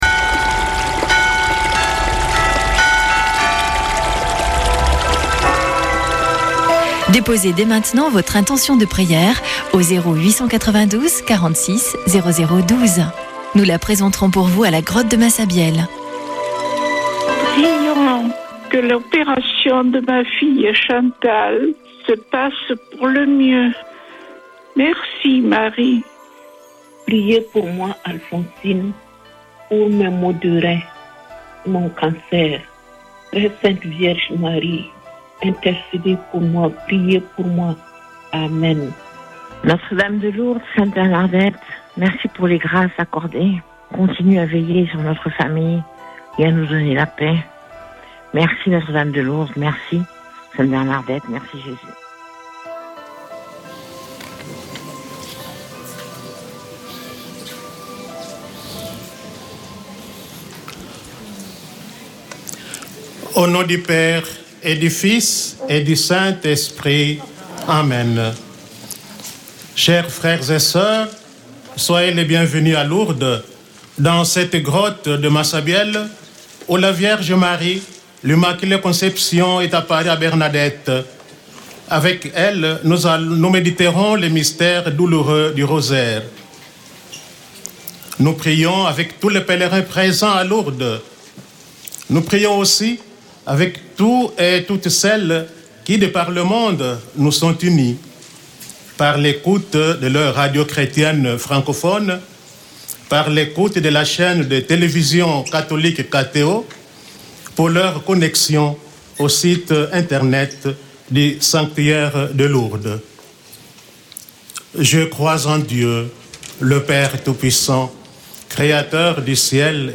Une émission présentée par Chapelains de Lourdes